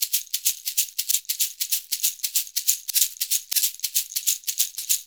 Index of /90_sSampleCDs/USB Soundscan vol.56 - Modern Percussion Loops [AKAI] 1CD/Partition B/07-SHAKER094